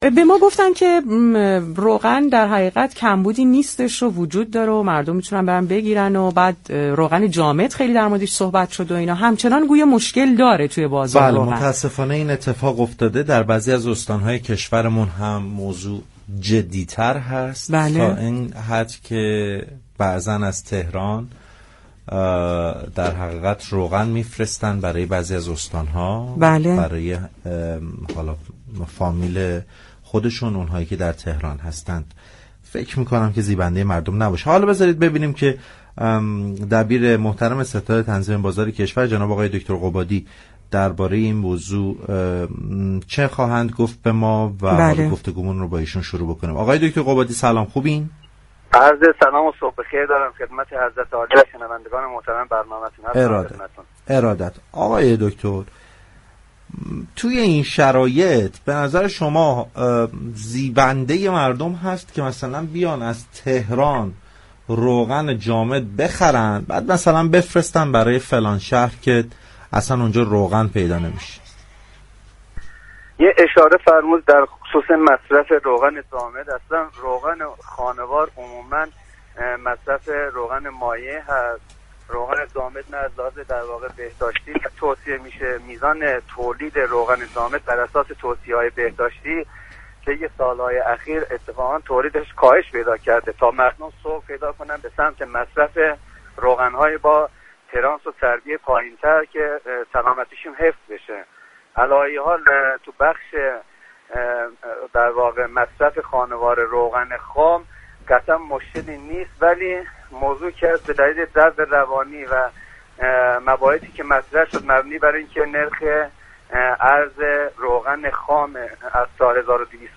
عباس قبادی دبیر ستاد تنظیم بازار كشور در گفتگو با برنامه پارك شهر رادیو تهران از افزایش تولید روغن مایع و جامد خبر داد و گفت: این مشكل به زودی حل می شود ولی رسانه ها نباید بحران را به جامعه القاء كنند.